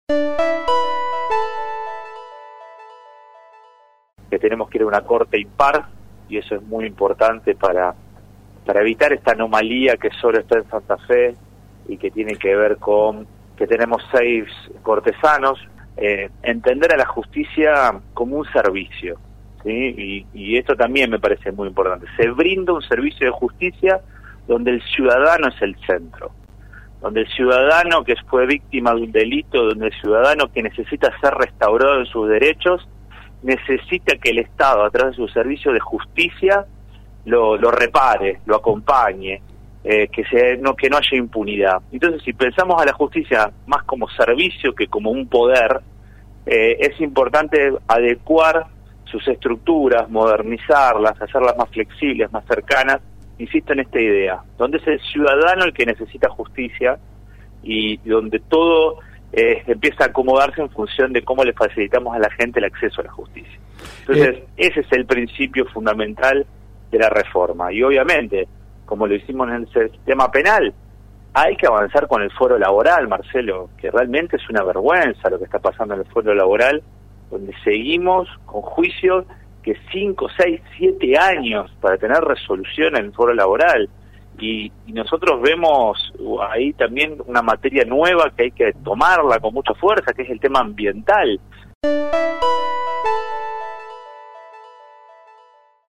En contacto con LT3 el diputado provincial Joaquín Blanco afirmó que es urgente avanzar en una reforma en materia laboral y sumar el derecho ambiental.